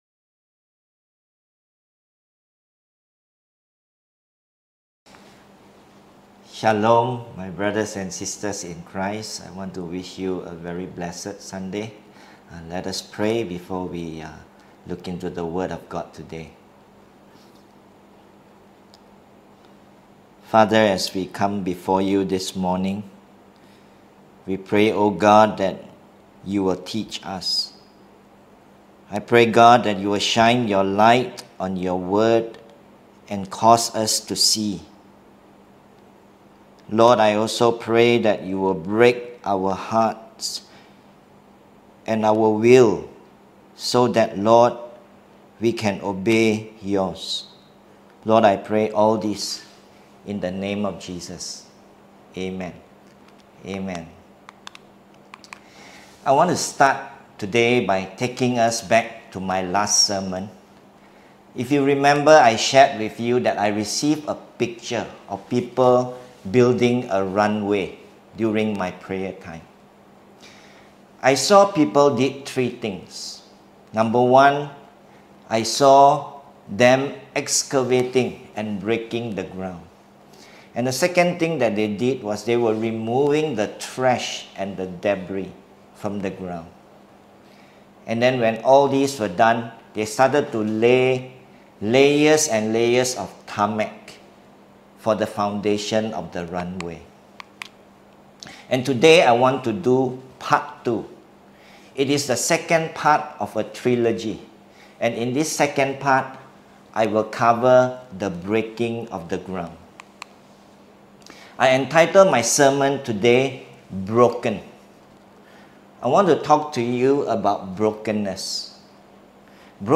Click here for the full version with worship ← Back to all sermons